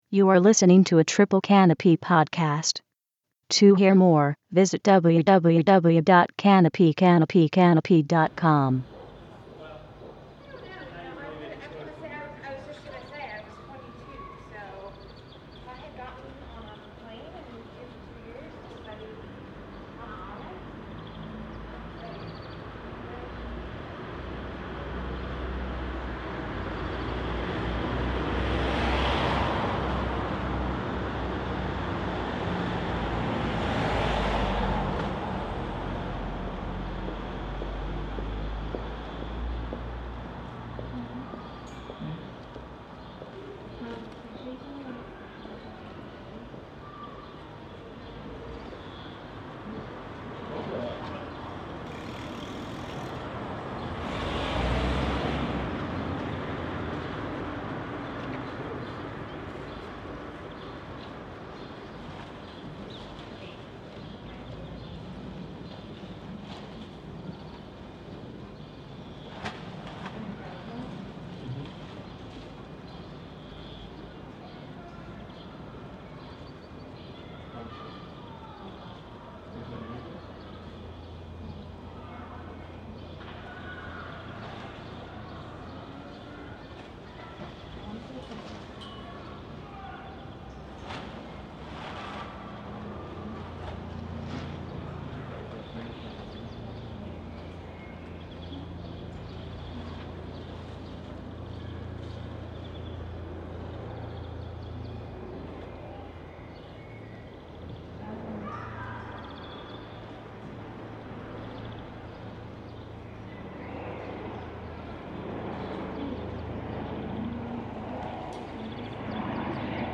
The program consisted of seven hours of sound work.